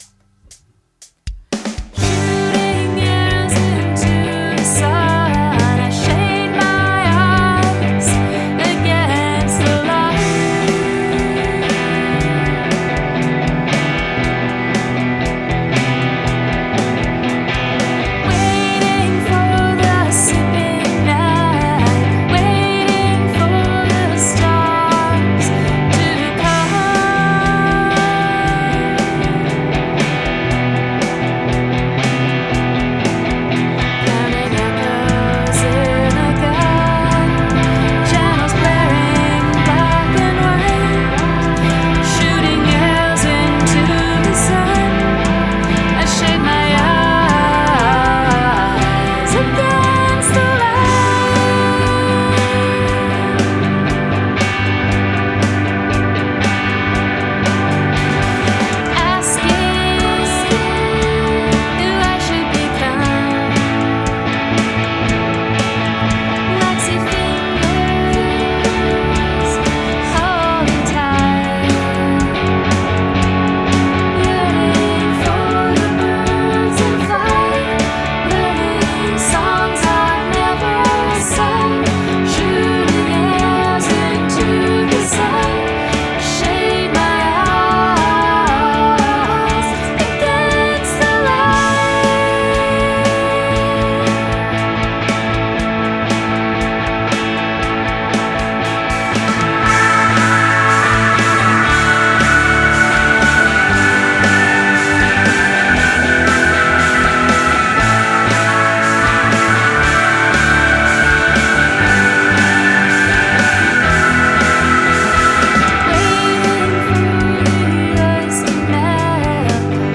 Rondel
I liked the tom builds on the second stanza.